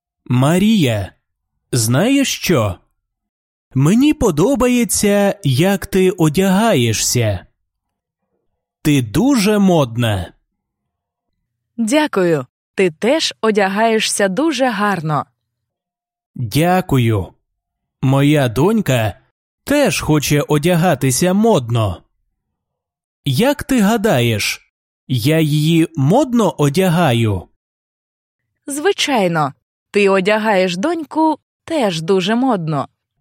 Dialogues
basic-ukrainian-lesson-07-dialogue-02.mp3